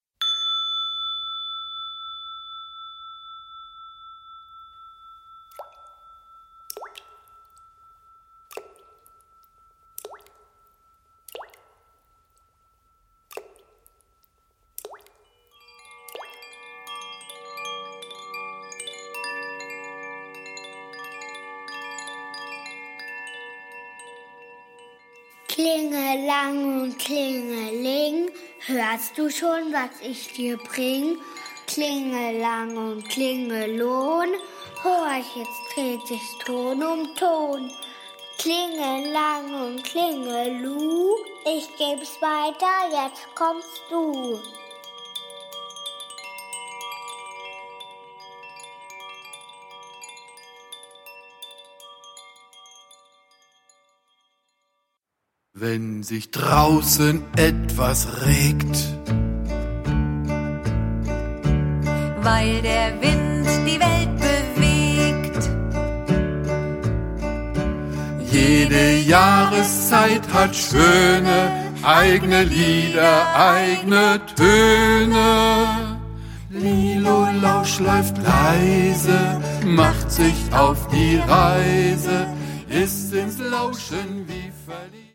Lieder vom Fühlen, Horchen und Achtsamsein
Die neuen Spiel- und Bewegungslieder
Kinderlieder